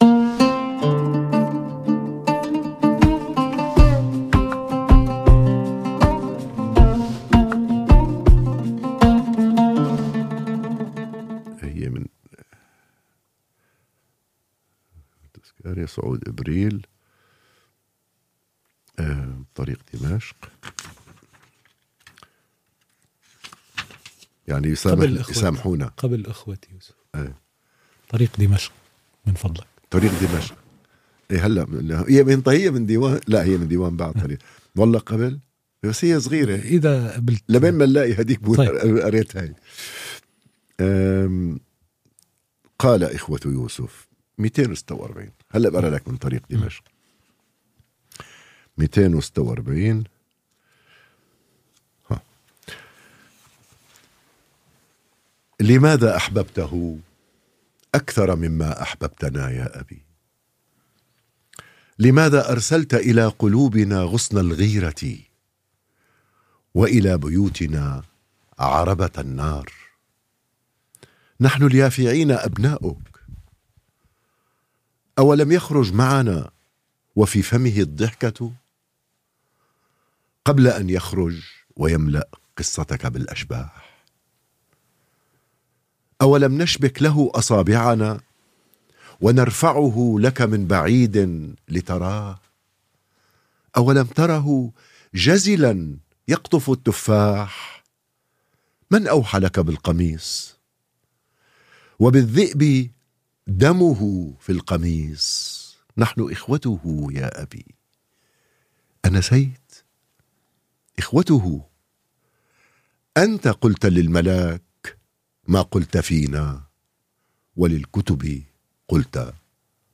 [AR] (2/2) Im Gespräch mit Nouri Al-Jarrah حوار الديوان مع نوري الجرّاح ~ DIVAN Podcasts Podcast